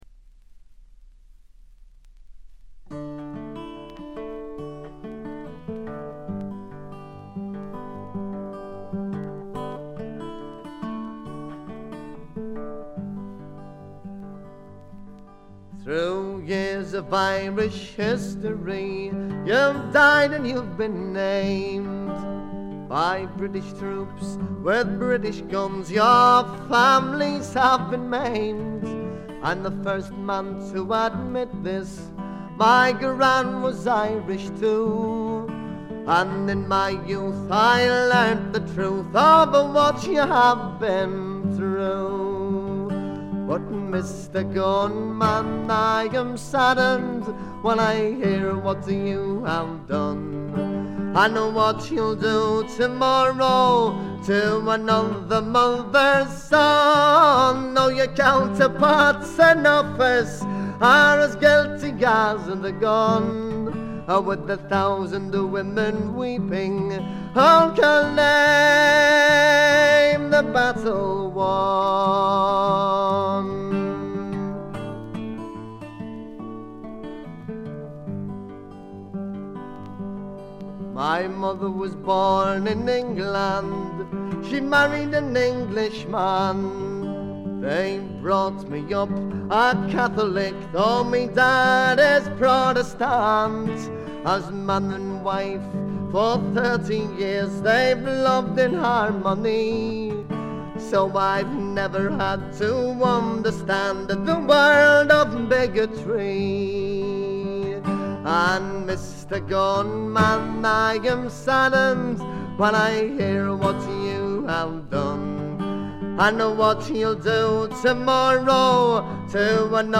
試聴曲は現品からの取り込み音源です。
Guitar ?
Tin Whistle ?